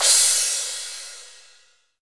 • Crash C Key 10.wav
Royality free crash one shot tuned to the C note.
crash-c-key-10-4IX.wav